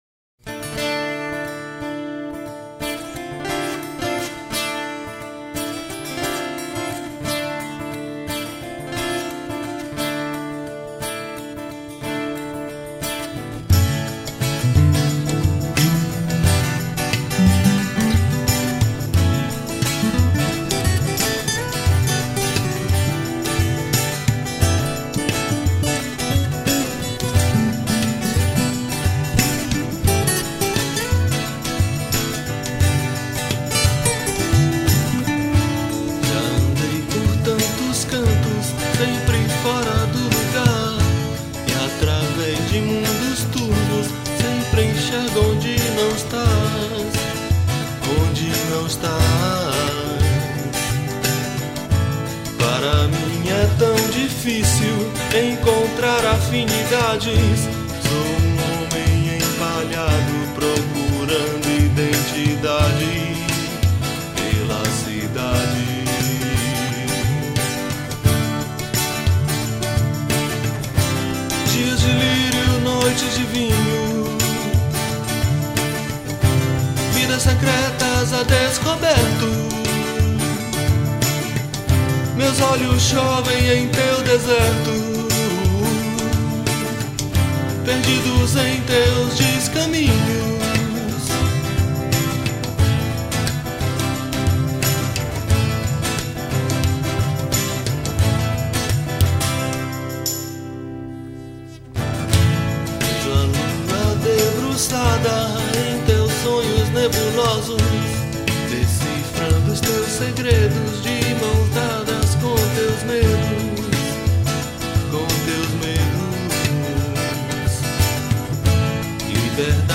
1042   03:38:00   Faixa:     Rock Nacional
Percussão